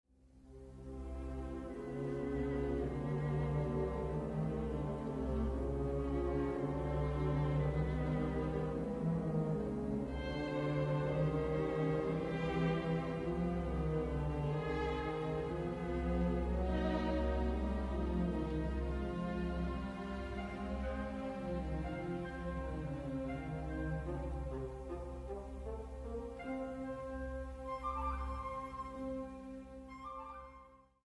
Początek czwartej części jest ostry i gwałtowny, a dęte blaszane naprawdę mogą sobie tu pograć.
Posłuchajcie snującego się w tle, ale wyraźnie słyszalnego akompaniamentu smyczków: